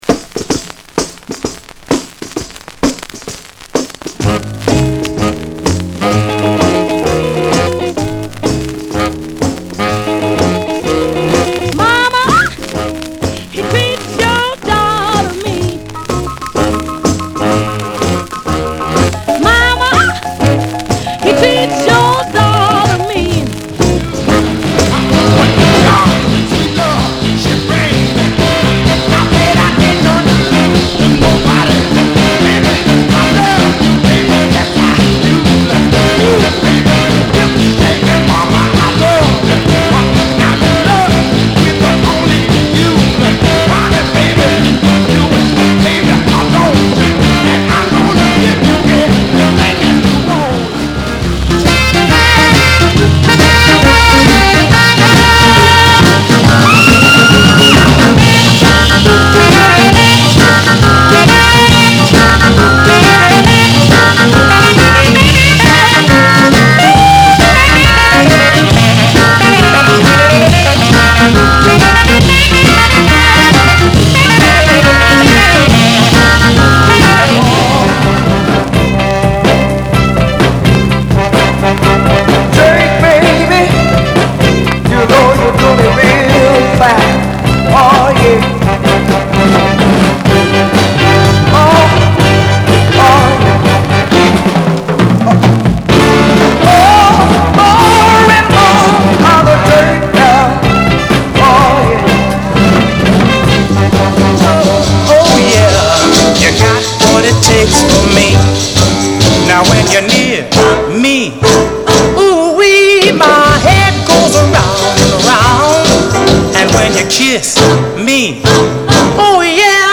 R&B、ソウル
/盤質/両面全体的に細かい傷あり/US PRESS